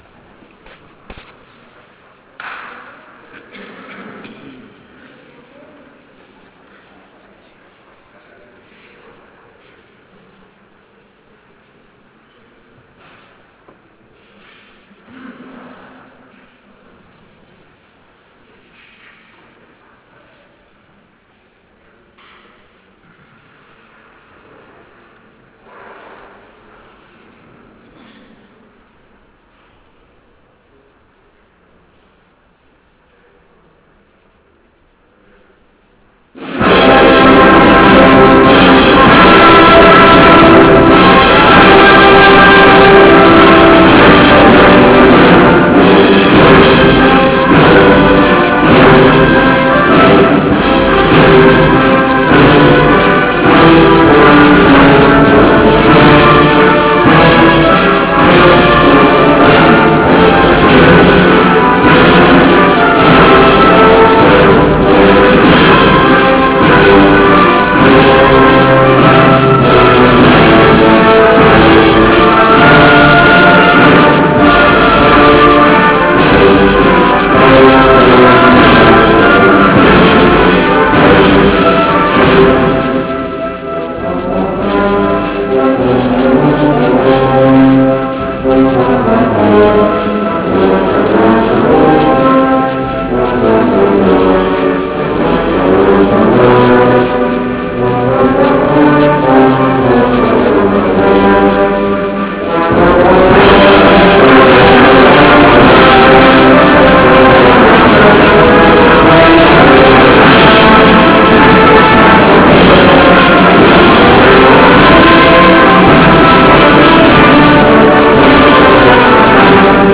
marcha